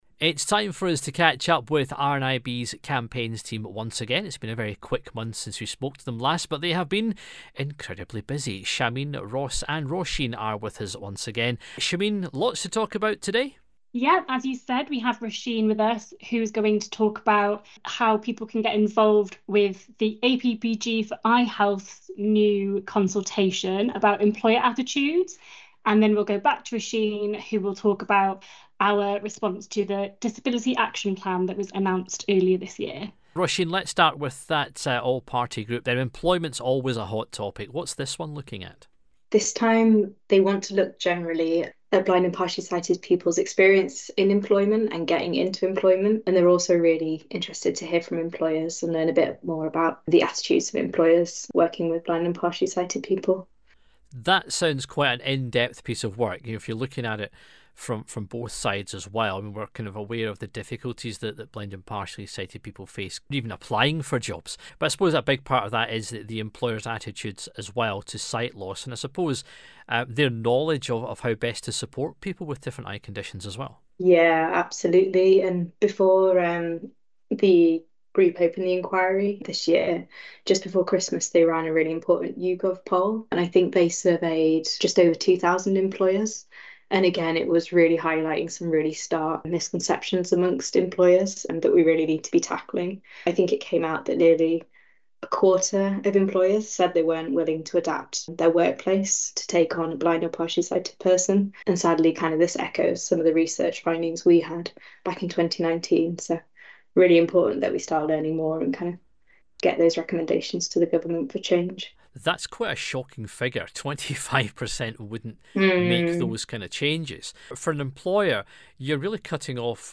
is joined by some of the RNIB Campaigns Team to discuss some of the big projects they’re involved in